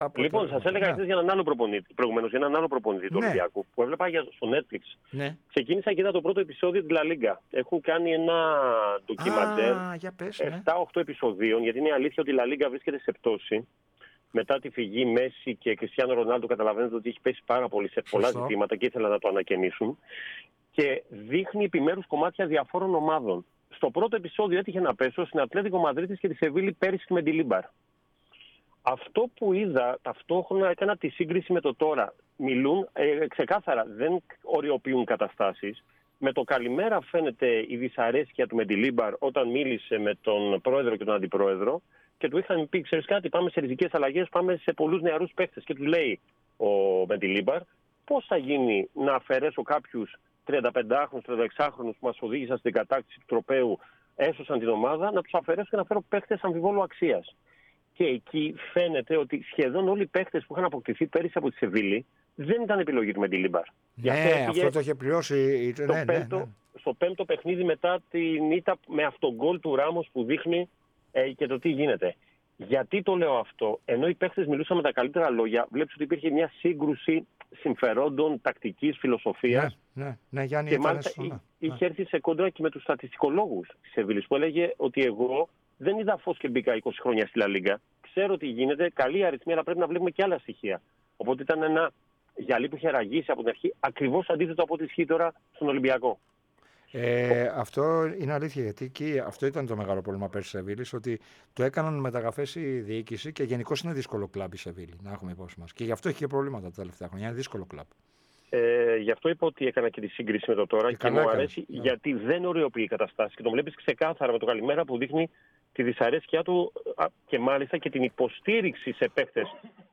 ΕΡΑ ΣΠΟΡ